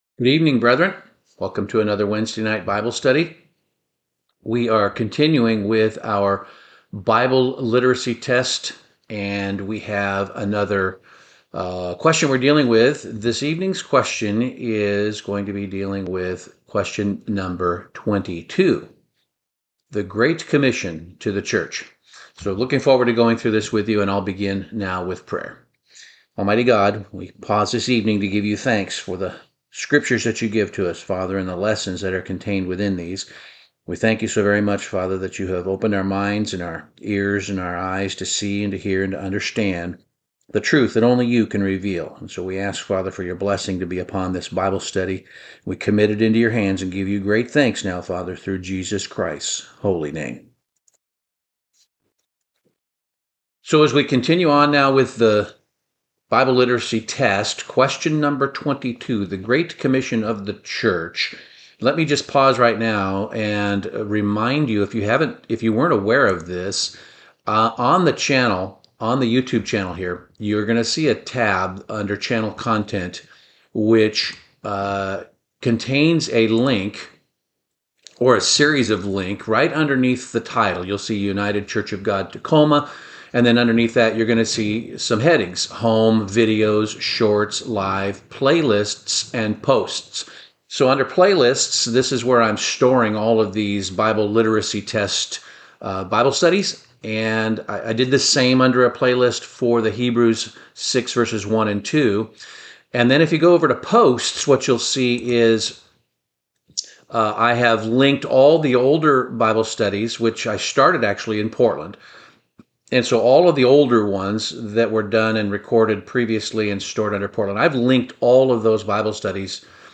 Bible Study - The Great Commission